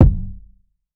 Slump Kick.wav